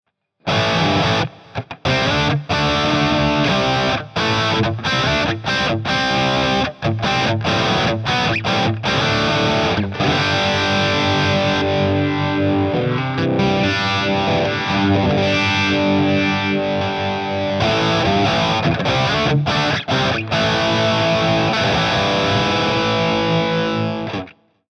Based on a Marshall Amp Stack.
All tones were recorded via USB straight into Logic X.
No post FX were added.
4. Big Wall – CTL Activates UNI-V
SOUND SAMPLES: (CTL Effects are engaged during the sound demo)